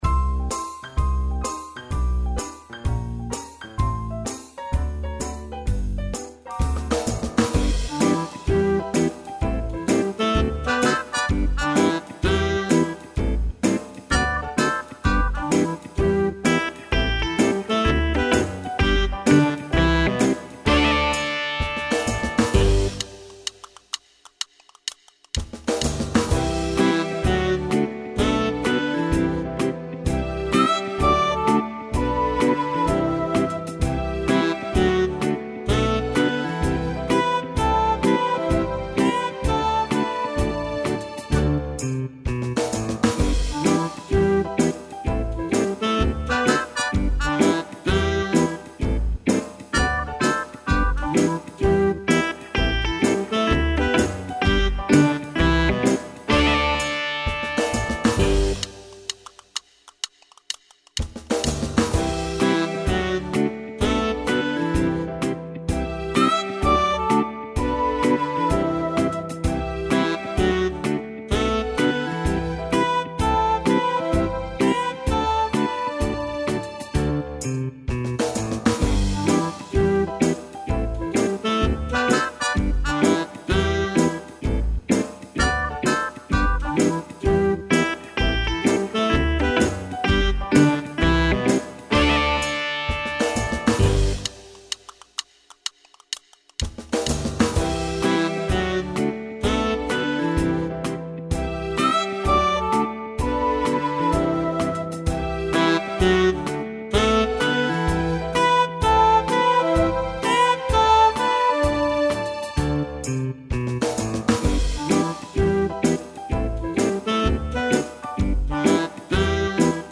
(backing track) mp3